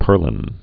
(pûrlĭn)